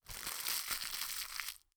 crumples1.wav